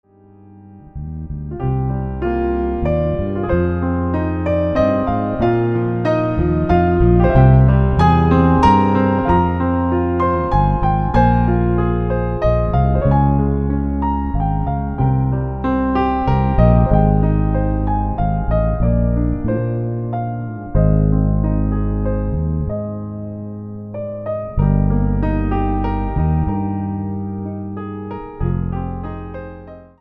もの悲しい雰囲気の遠近感を感じさせる佳曲